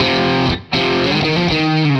Index of /musicradar/80s-heat-samples/120bpm
AM_HeroGuitar_120-F02.wav